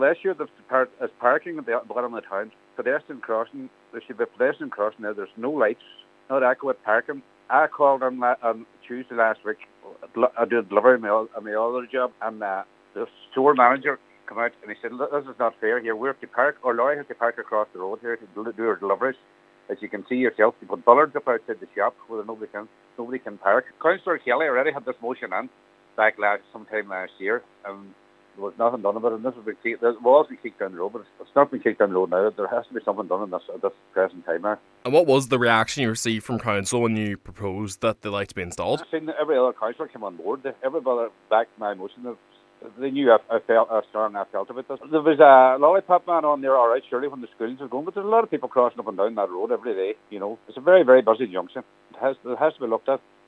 Donegal County Council rejected the call, saying installing lights and a crossing at the junction would negatively impact the traffic flow in the area – but despite this Cllr Bradley says support from his fellow councillors means this must be looked at again: